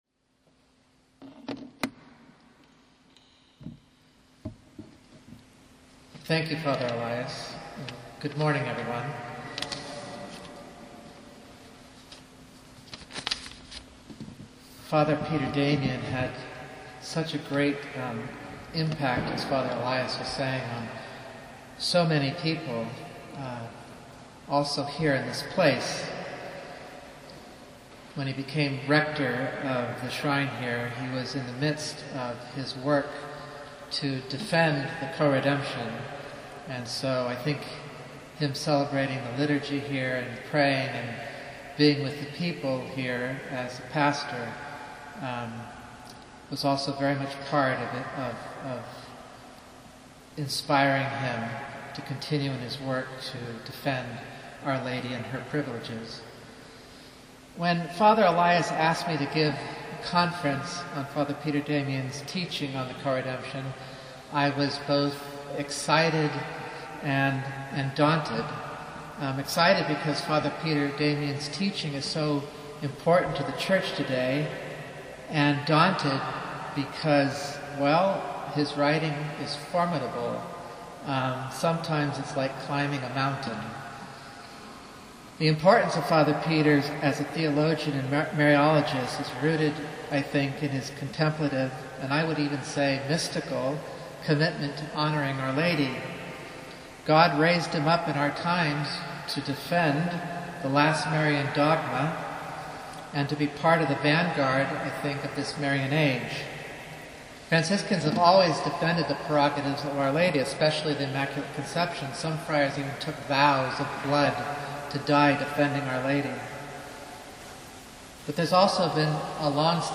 the first talk